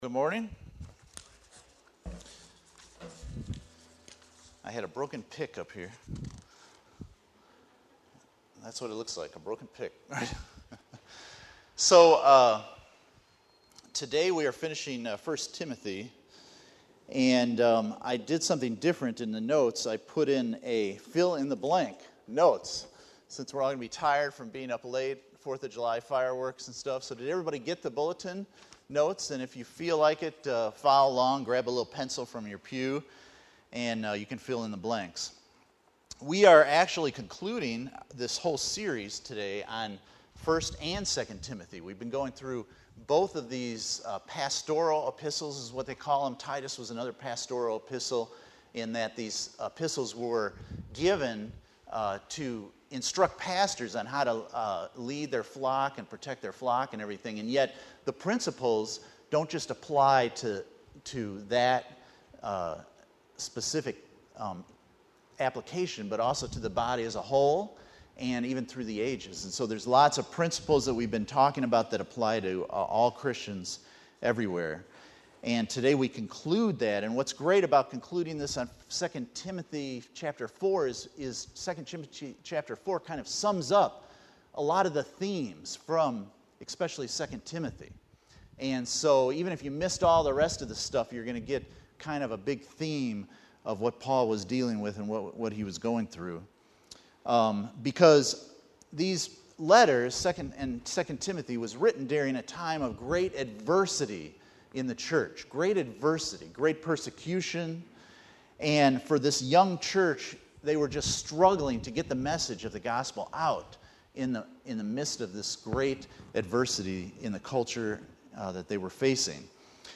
Letters to Timothy Passage: 2 Timothy 4:9-22 Service Type: Sunday Morning %todo_render% « Preach the Word Elohim